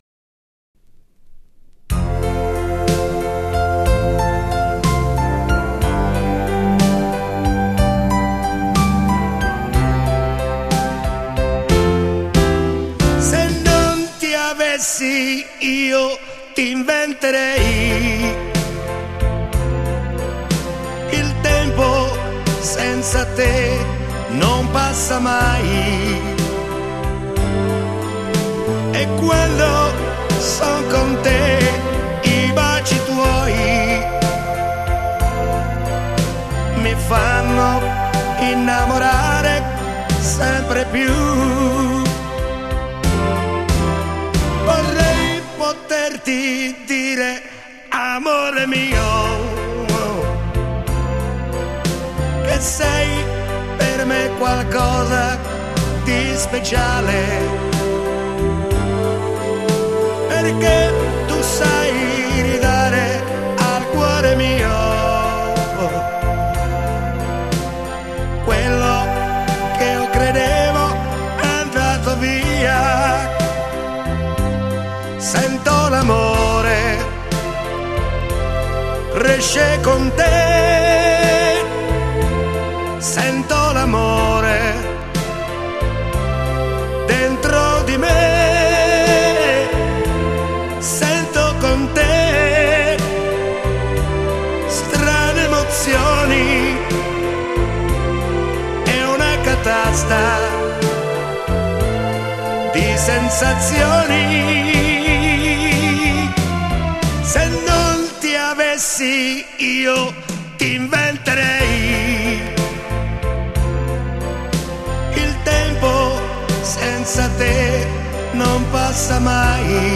Genere: Lento